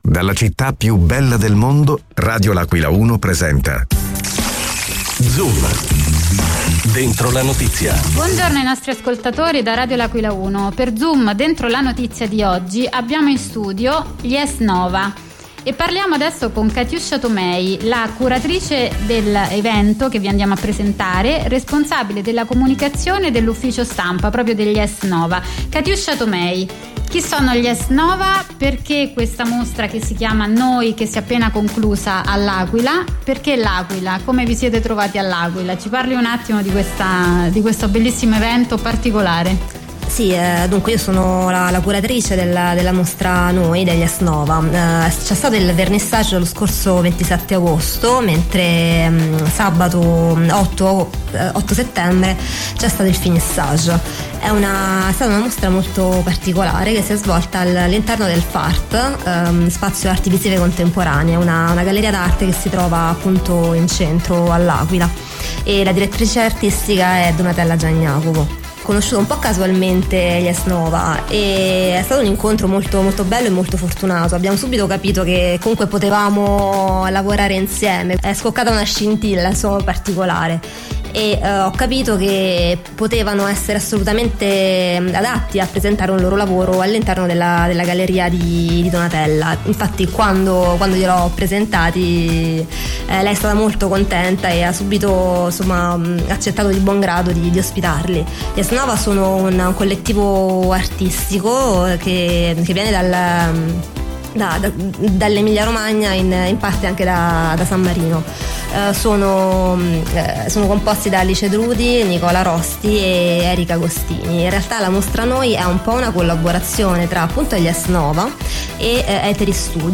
Ma perché il buio e cosa rappresenta per gli Es Nova? Gli abbiamo chiesto questo e tanto altro, all’interno della nostra rubrica Zoom dentro la Notizia.